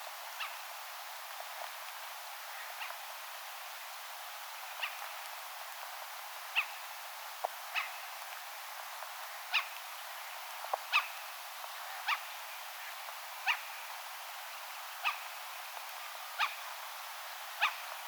kalalokkiemon varoitus poikasille -
Kun kalalokeilla on poikaset,
niin niillä on joitakin ääniä,
joita ei muulloin kuule ollenkaan.
olisiko_kalalokkiemon_varoitus_poikasille_mika_laji.mp3